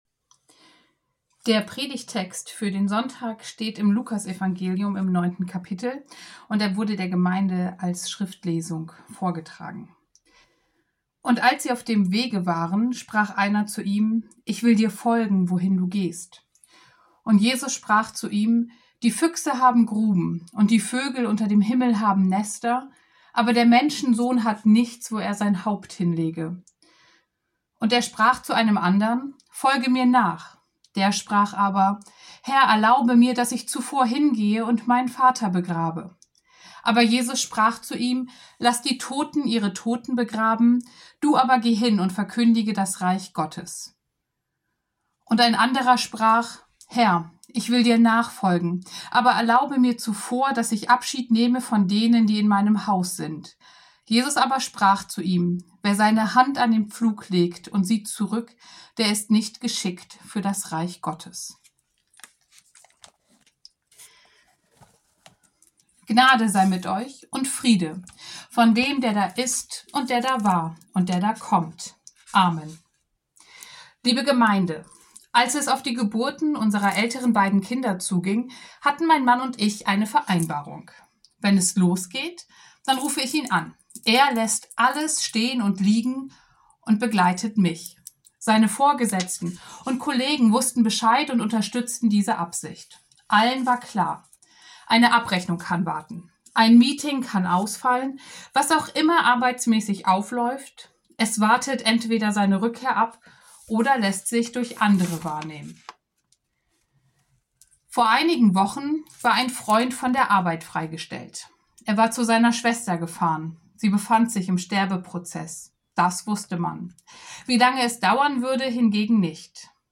Predigt zu Okuli